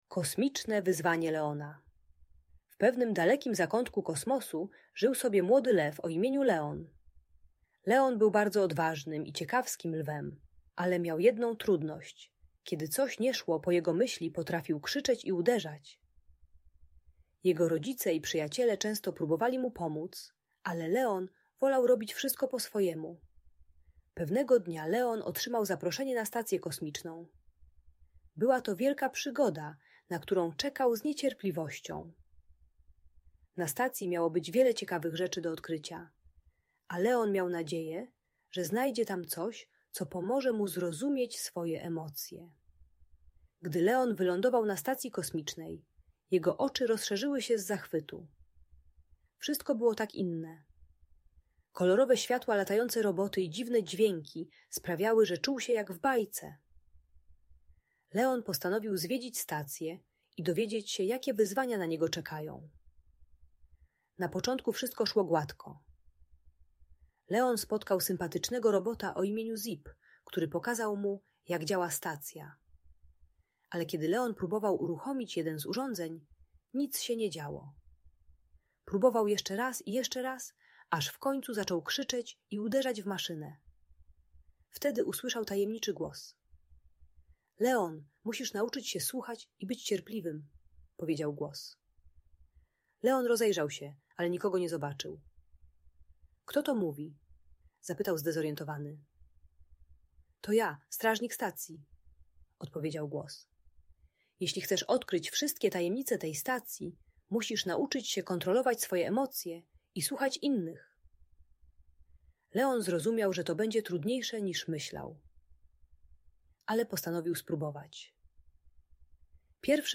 Kosmiczne Wyzwanie Leona - Bunt i wybuchy złości | Audiobajka